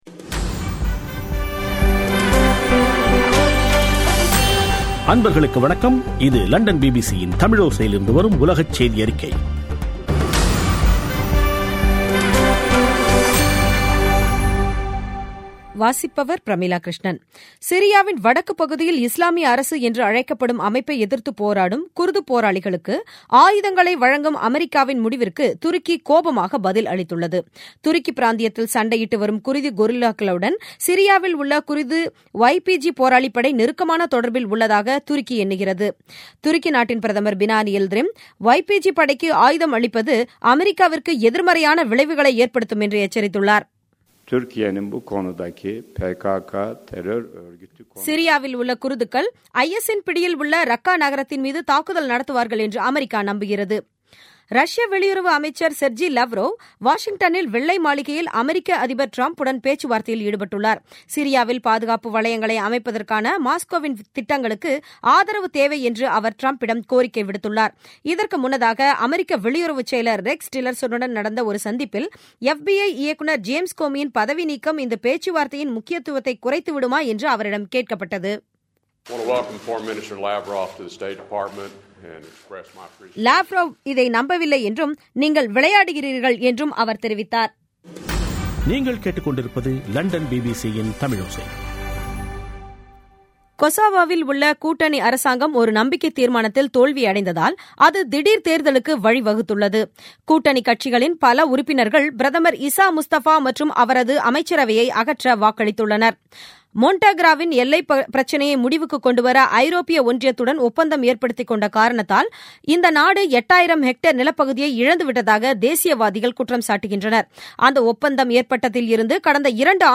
பிபிசி தமிழோசை செய்தியறிக்கை (10/05/2017)